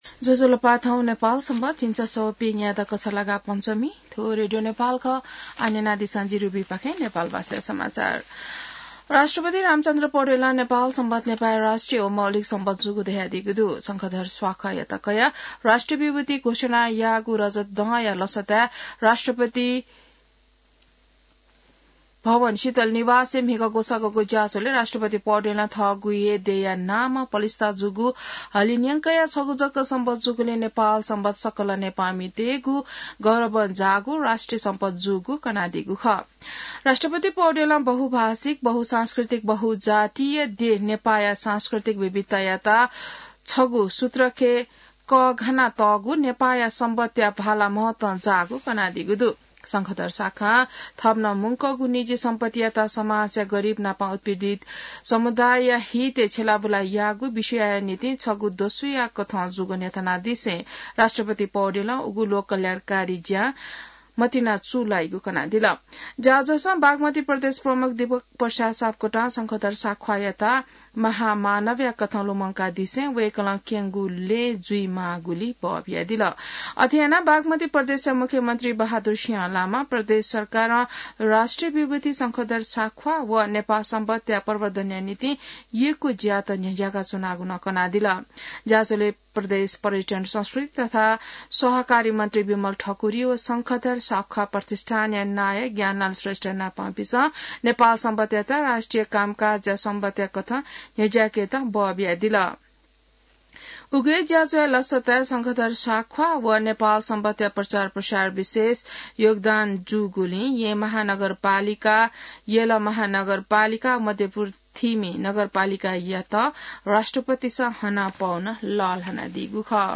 नेपाल भाषामा समाचार : ६ मंसिर , २०८१